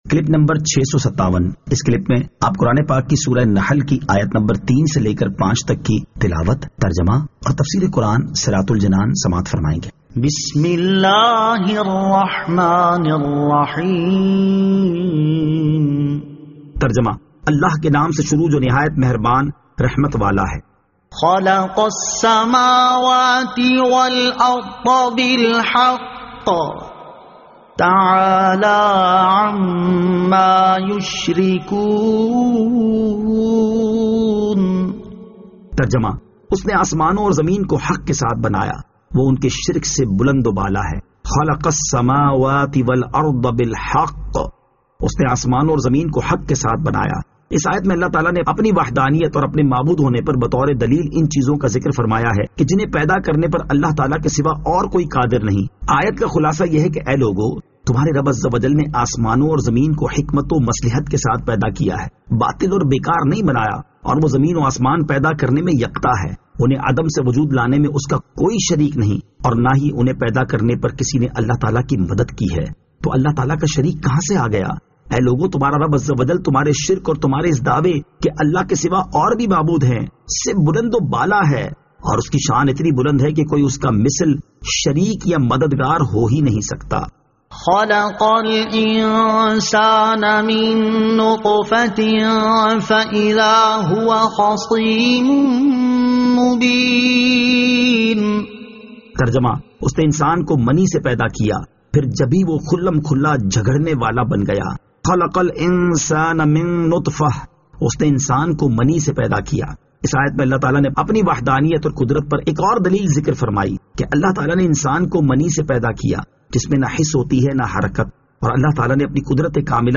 Surah An-Nahl Ayat 03 To 05 Tilawat , Tarjama , Tafseer